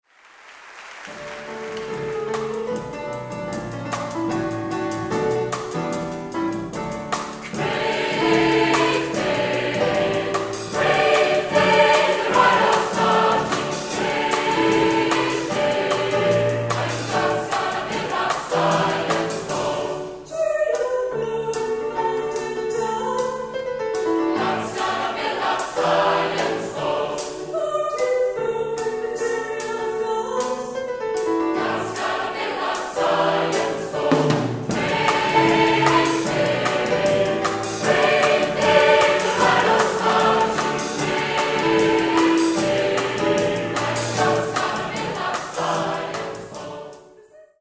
Demo-Aufnahmen - größtenteils Live-Mitschnitte.